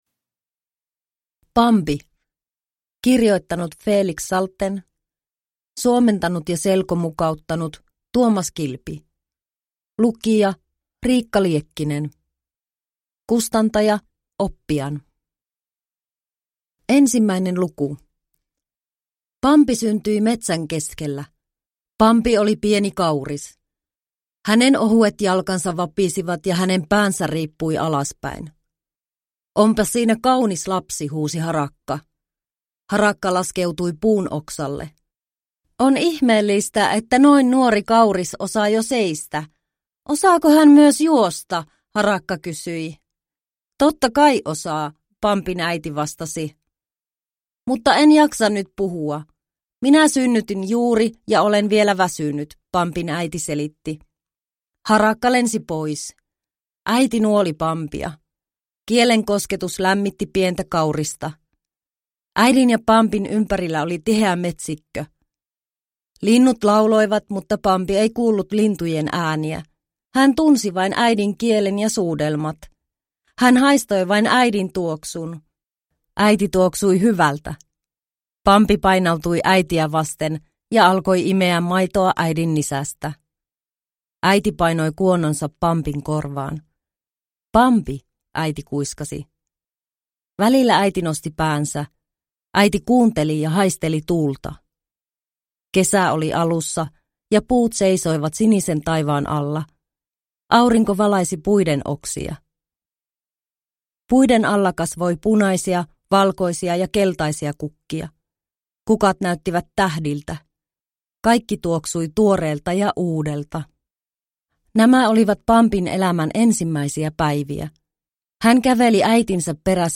Bambi (ladattava selkoäänikirja) – Ljudbok